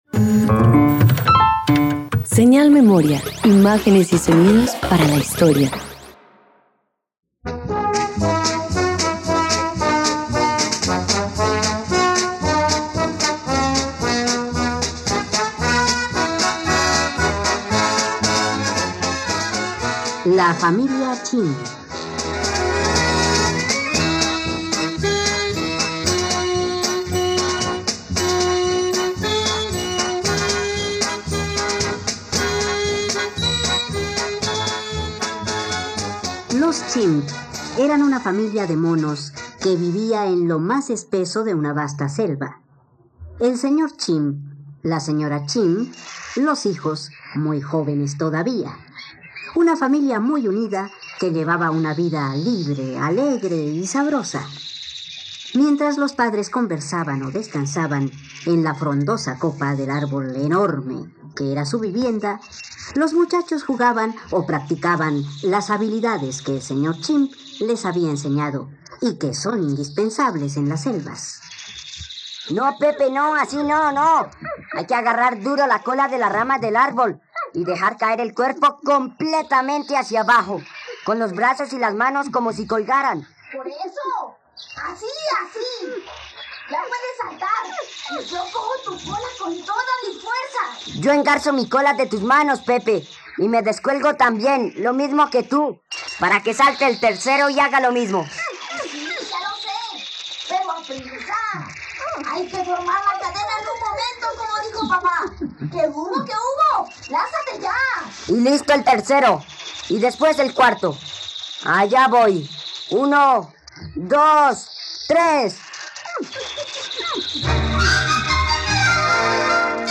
La familia Chimp - Radioteatro dominical | RTVCPlay